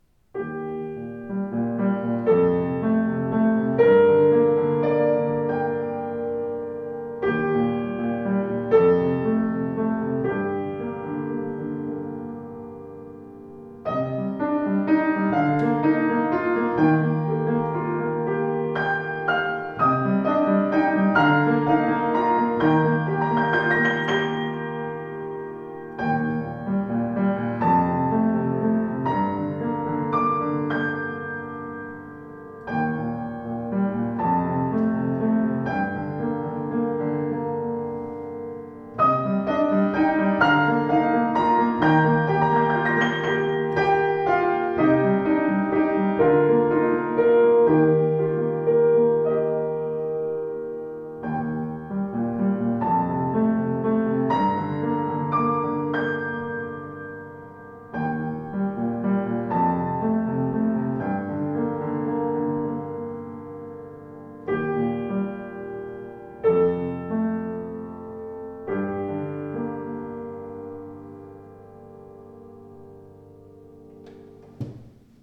Klavier Sauter 114 Esche
Voller, gestaltungsfähiger Klang, angenehm flüssige Spielart.